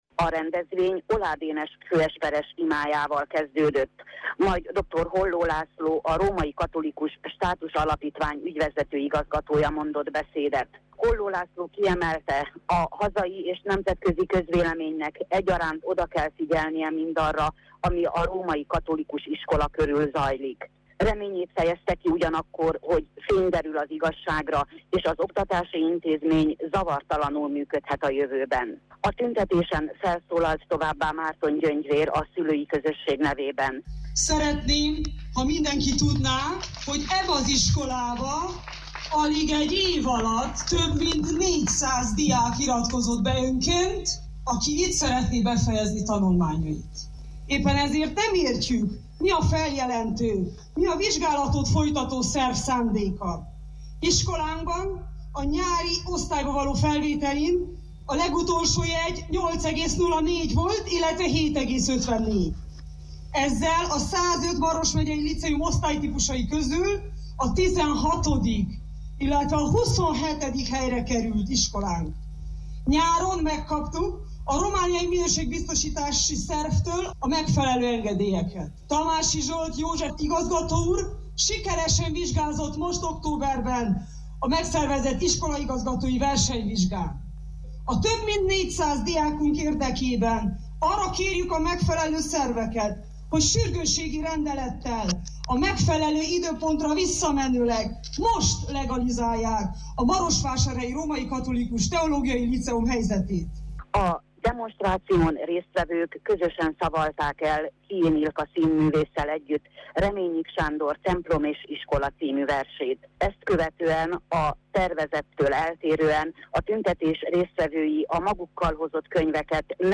tudósítása.